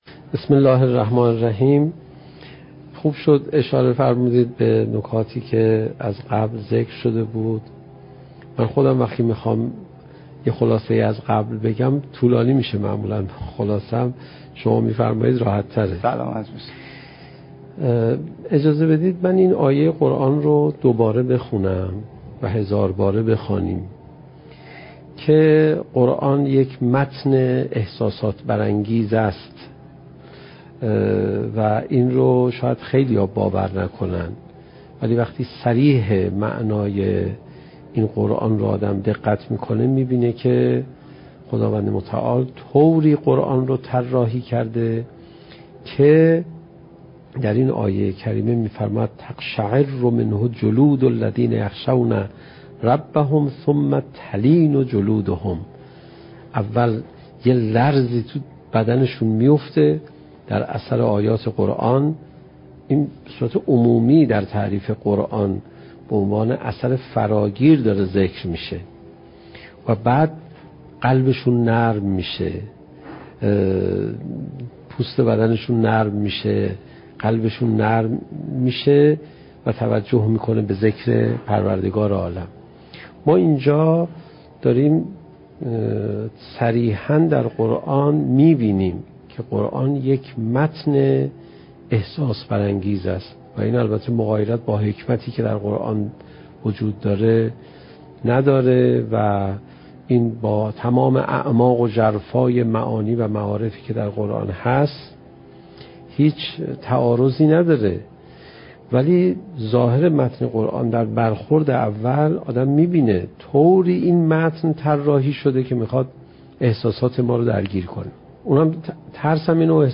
سخنرانی حجت الاسلام علیرضا پناهیان با موضوع "چگونه بهتر قرآن بخوانیم؟"؛ جلسه هفتم: "قرآن، کتاب احساسات"